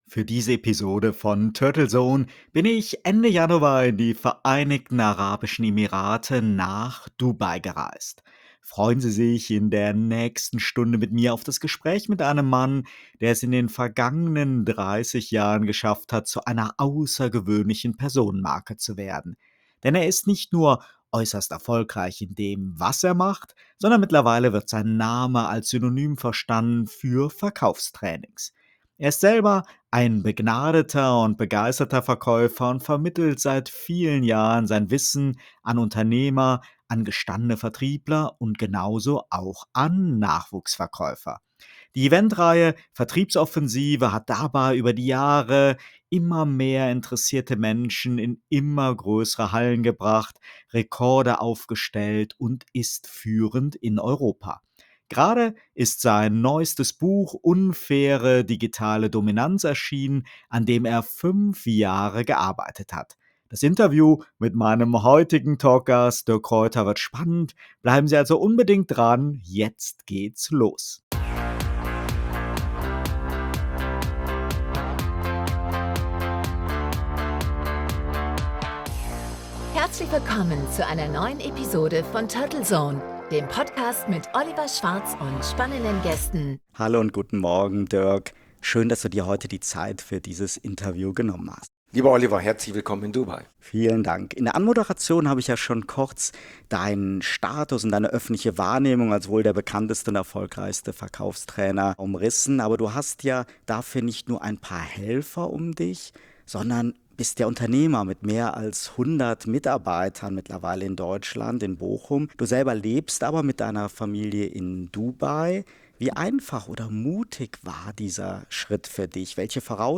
Ein spannendes Interview mit vielen wertvollen Impulsen.